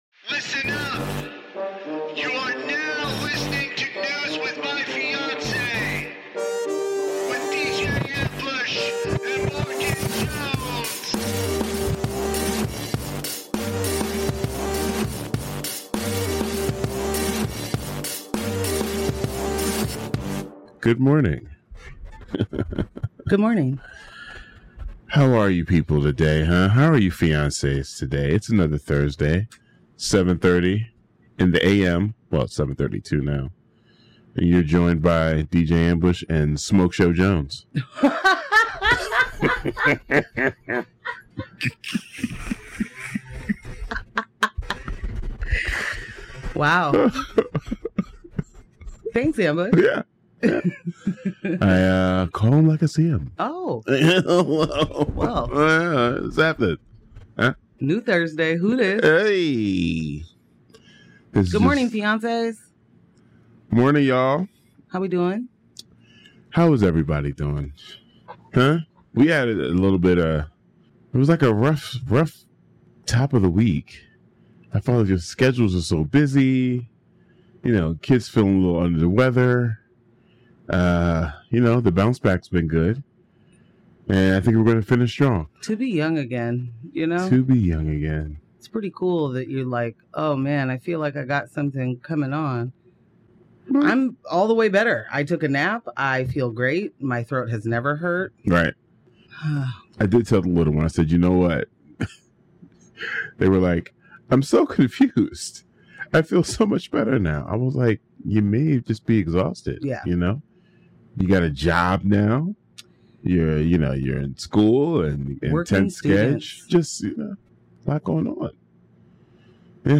Intro music